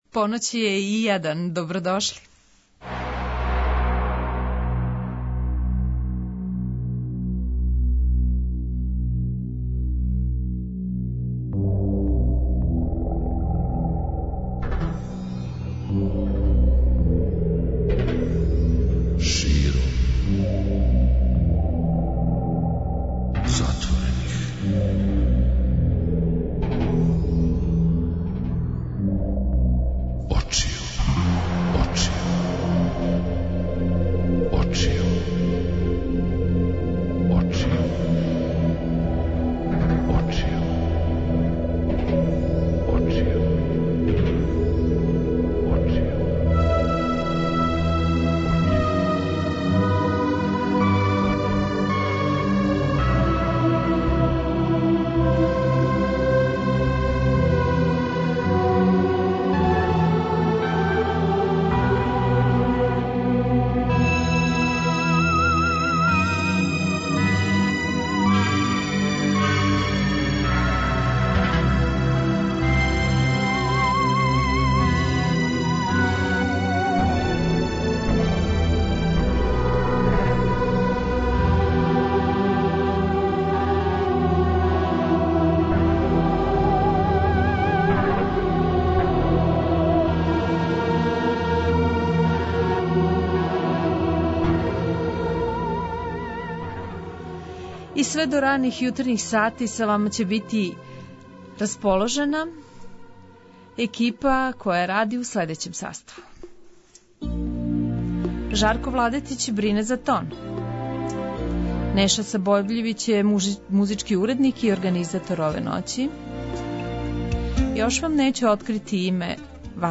Гост: Жарко Данчуо, музичар